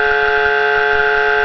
AVISADOR ACÚSTICO ELECTROMAGNÉTICO - SONIDO CONTINUO
Avisador acústico electromagnético
Elevado rendimiento acústico
Sonido continuo
108dB